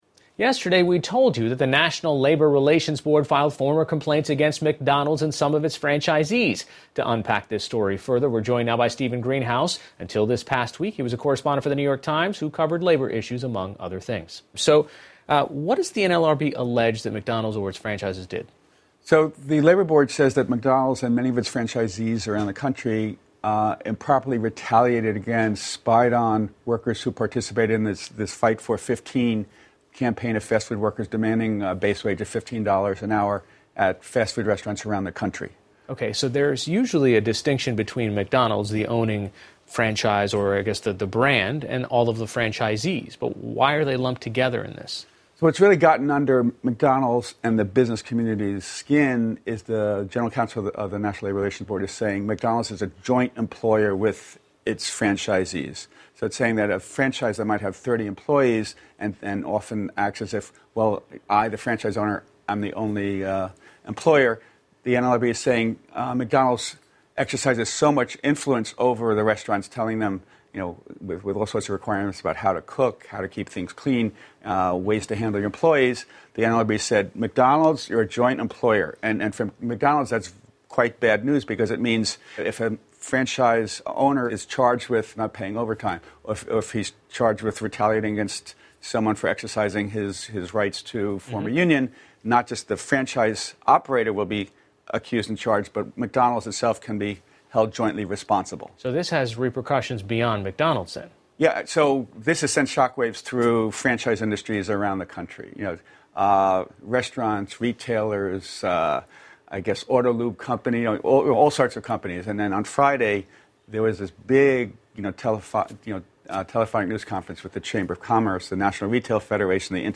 PBS高端访谈:美国麦当劳遭起诉 被指"窃取"员工工资 听力文件下载—在线英语听力室